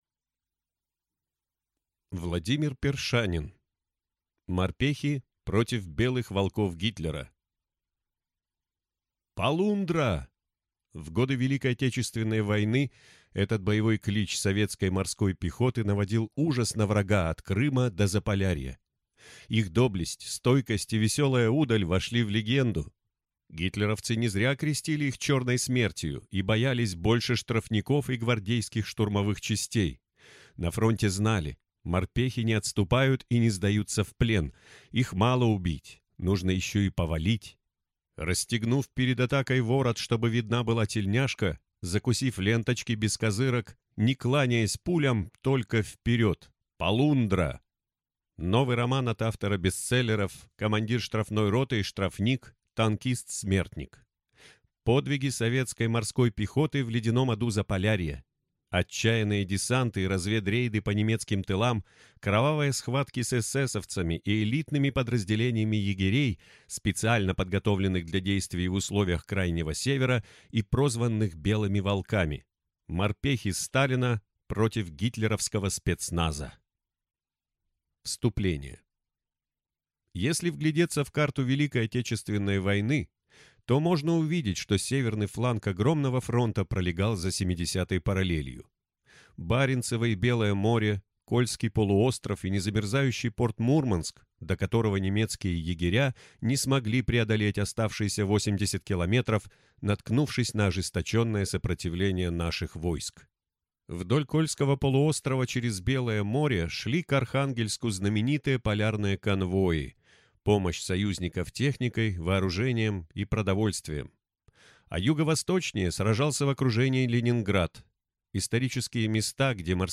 Аудиокнига Морпехи против «белых волков» Гитлера | Библиотека аудиокниг